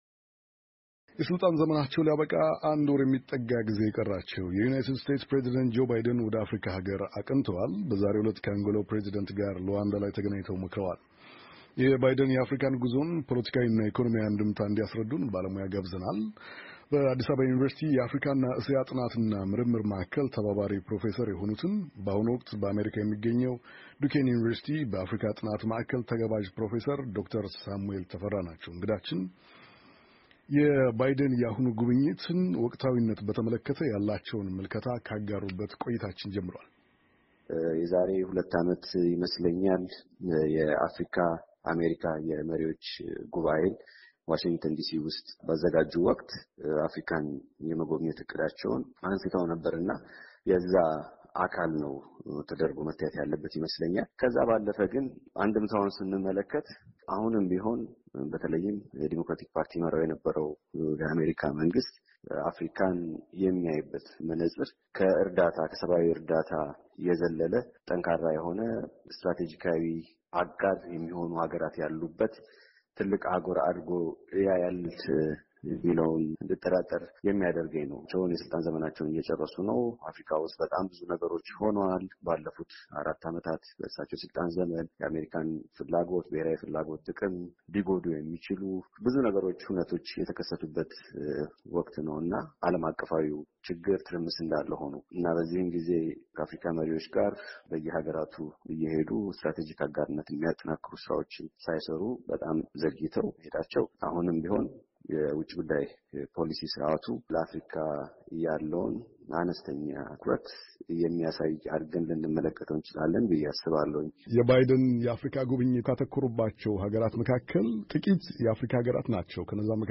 የባይደን የአፍሪካ ጉዞን ፖለቲካዊ እና ኢኮኖሚያዊ አንድምታ እንዲያስረዱን ባለሞያ ጋብዘናል።